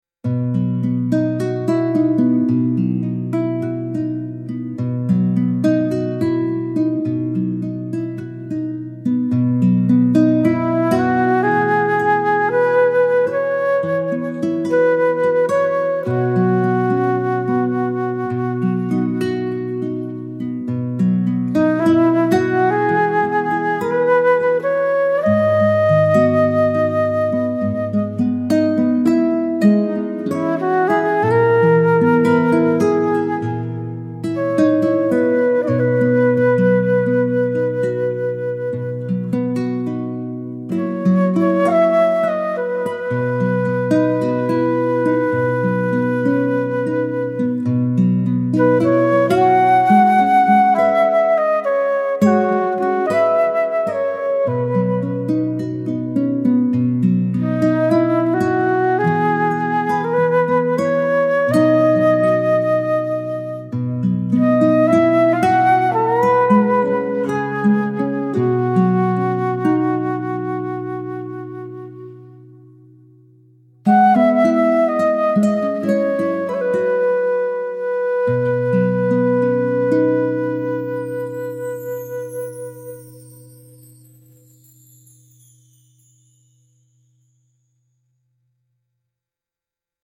intimate acoustic love song with fingerpicked nylon guitar and breathy flute